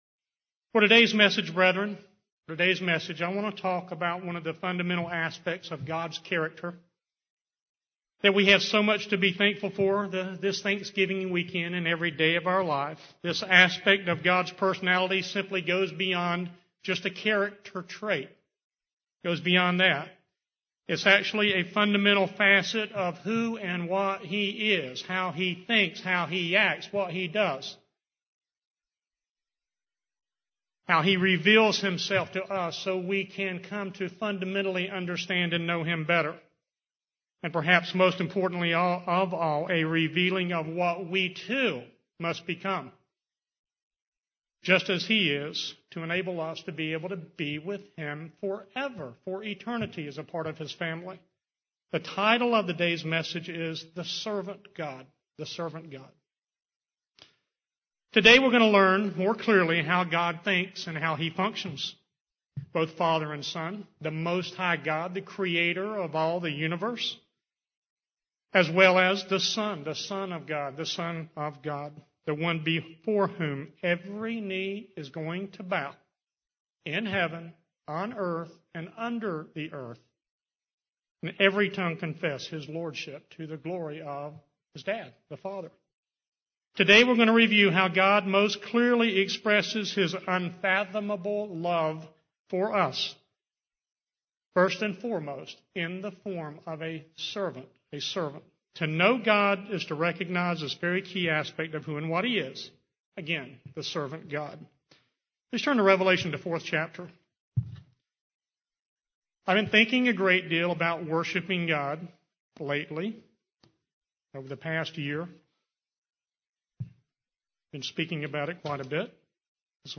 Given in Raleigh, NC
UCG Sermon Studying the bible?